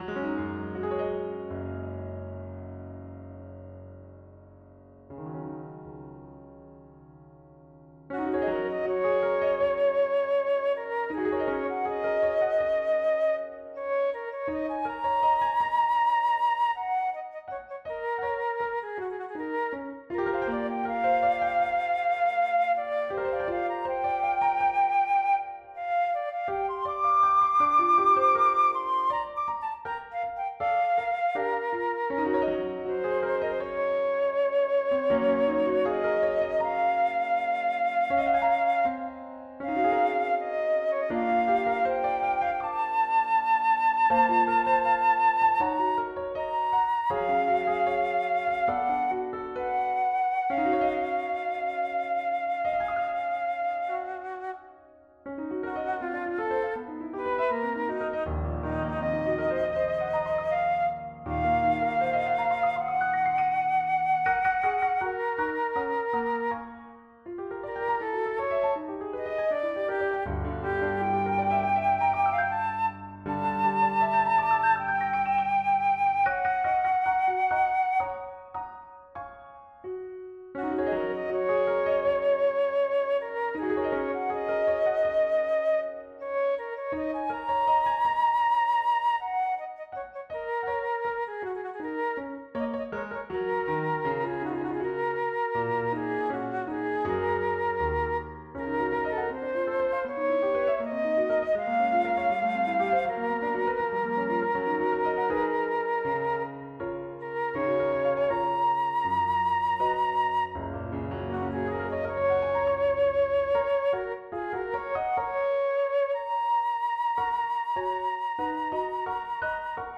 The third is marked andante, delicate fioratura for both instruments and with a harmonic shift between arches.